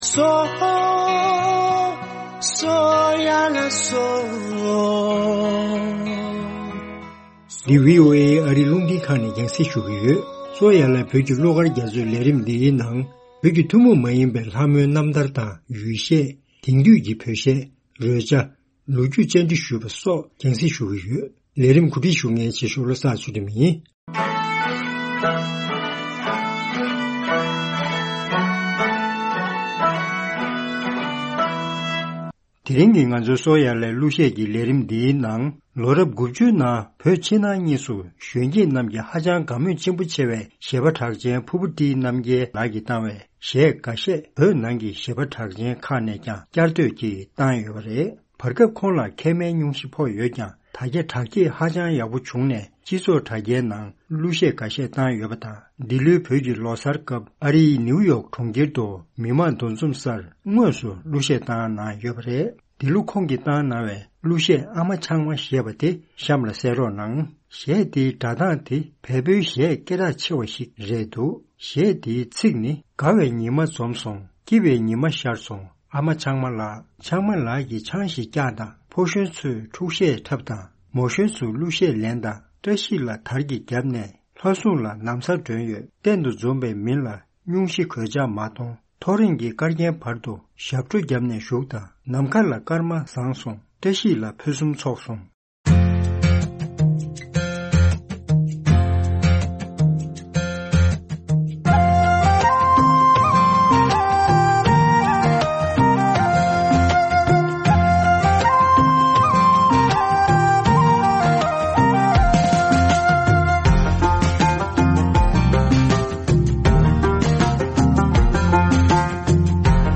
ཆང་གཞས